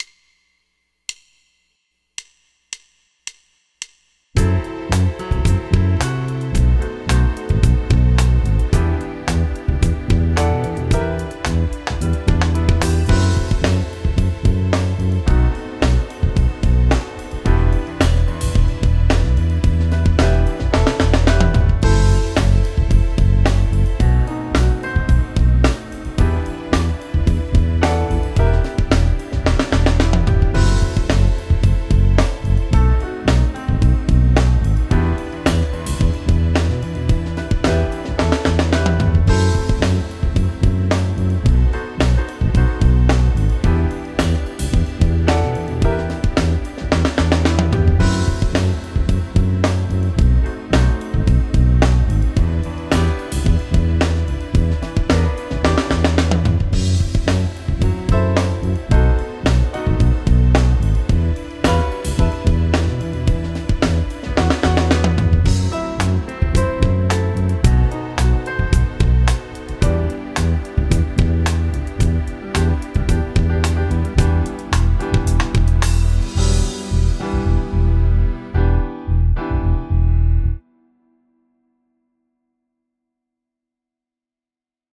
Minor 2 5 1 practice 110 bpm m9nor 251